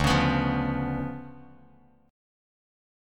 DmM13 chord